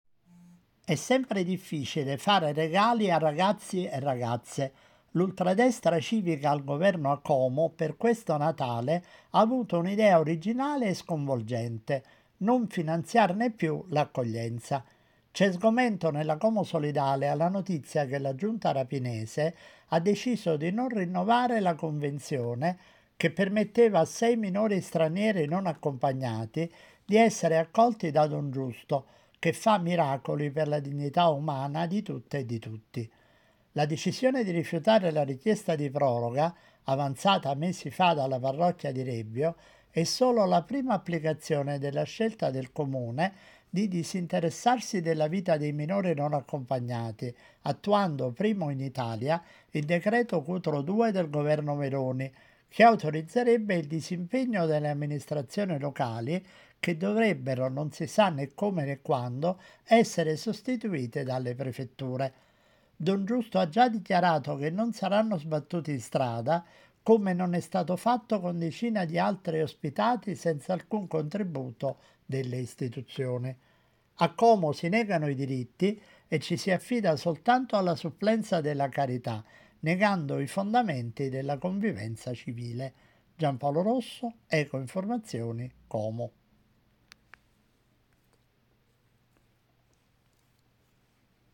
Il servizio
nell’edizione del 22 dicembre alle 7,15 di Metroregione di Radio popolare.